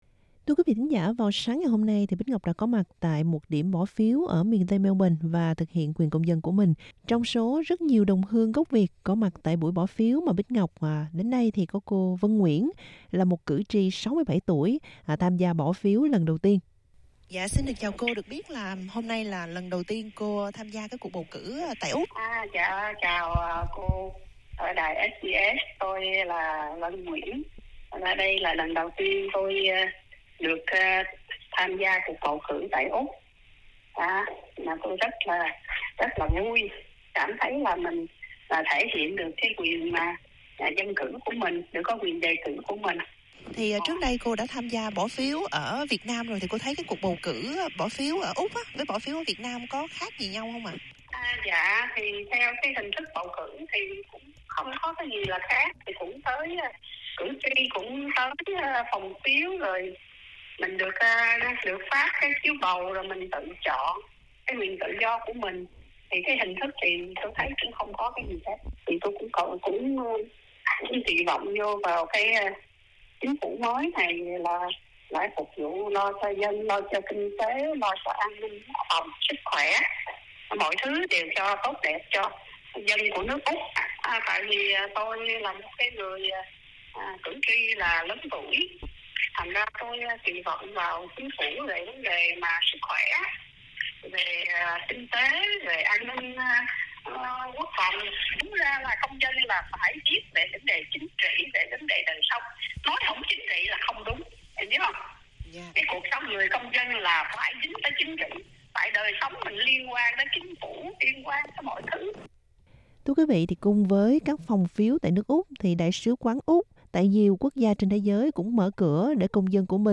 vietnamese_electionday_report.mp3